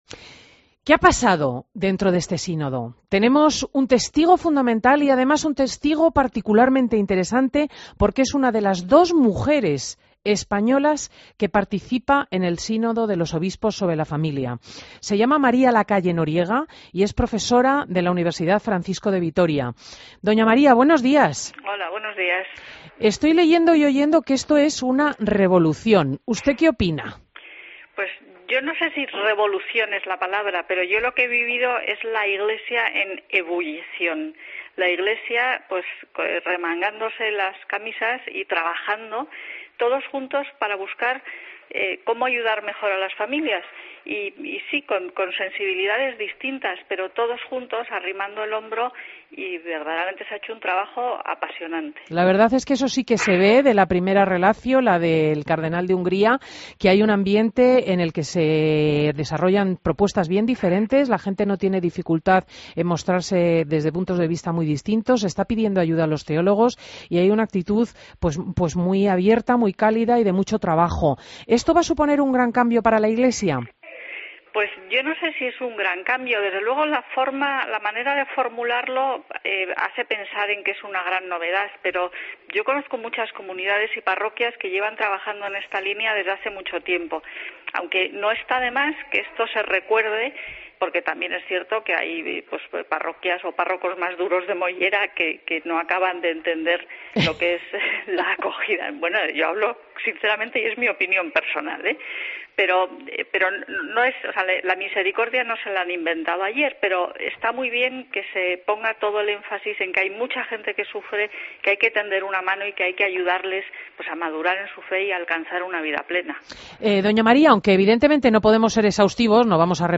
Entrevistas en Fin de Semana Fin Semana COPE